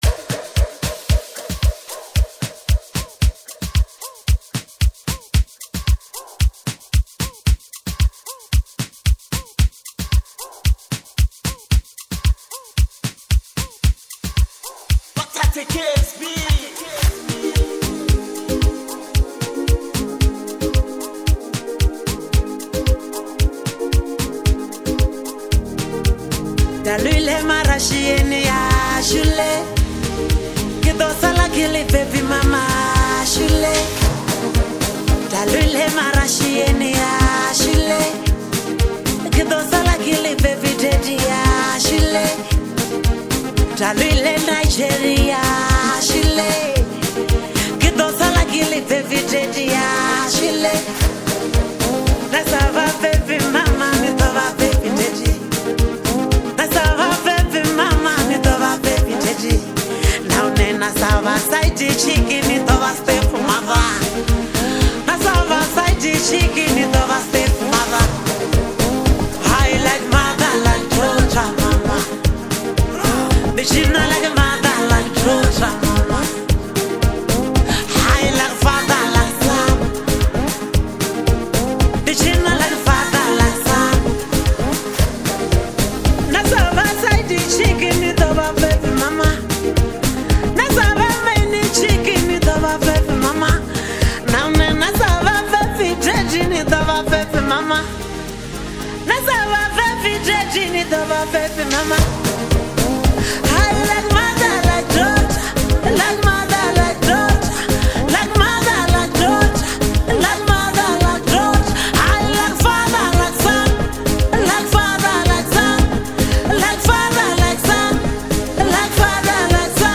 a powerful anthem
commanding vocal power